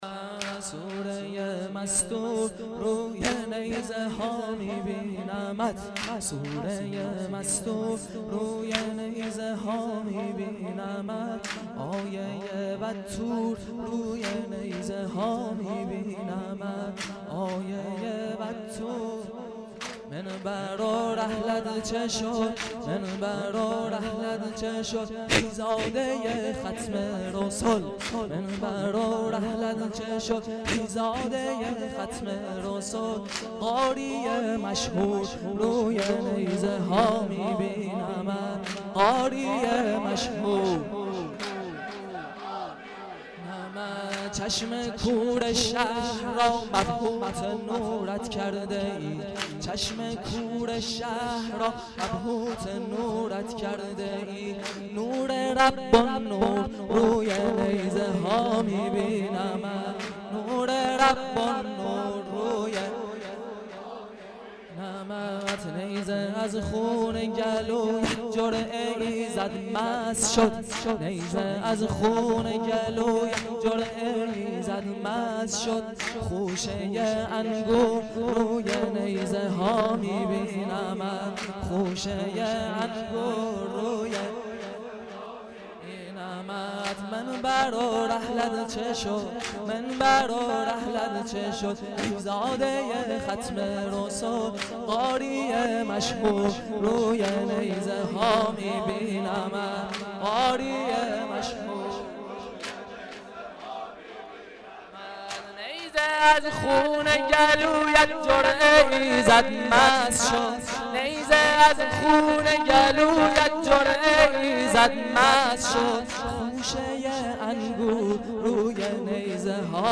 سنگین چکشی